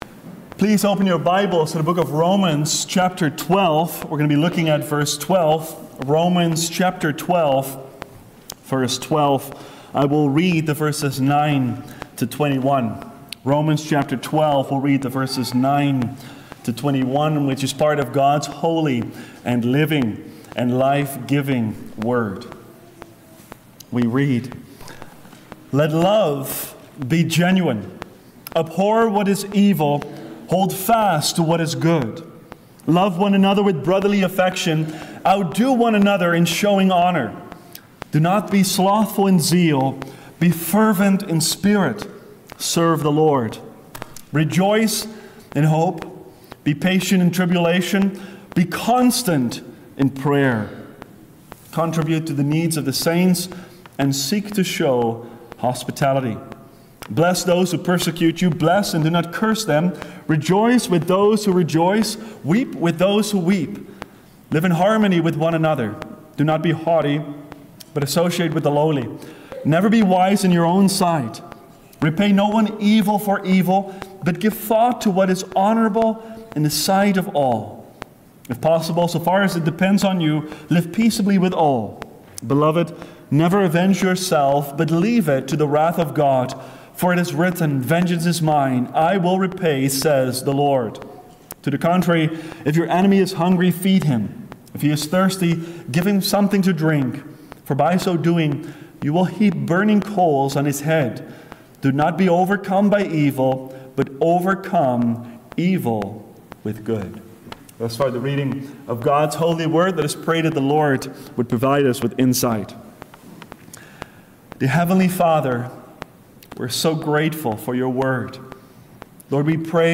Living in Hope, Patience, and Prayer – Seventh Reformed Church